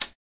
乌干达 "木制门锁解锁死锁与旧钥匙开和关的问题
描述：门木int锁解锁deadbolt与旧钥匙和offmic.wav
Tag: 按键 木材 开锁 门栓 INT